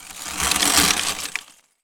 ice_spell_freeze_ground_01.wav